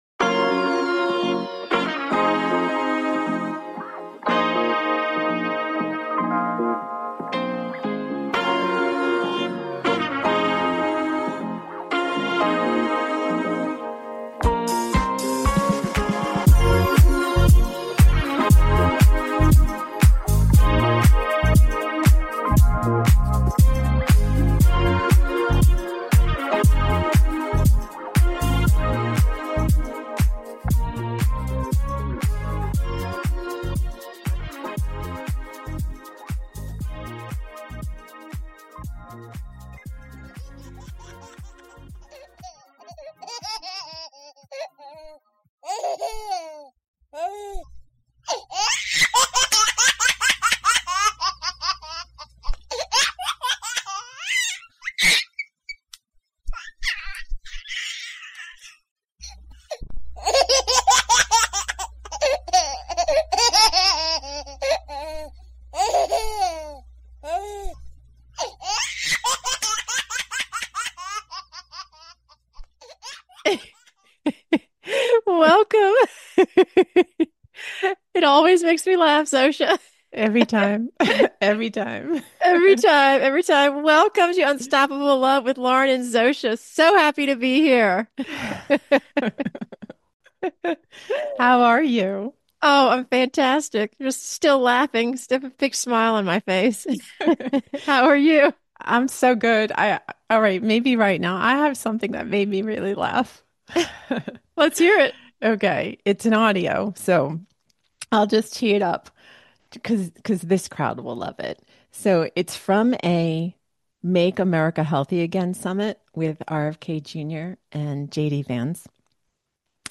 Talk Show Episode
Her preferred communication style is spontaneous and organic, while creating an environment of authenticity and peace, so sharing from their hearts is easy and natural for her listeners.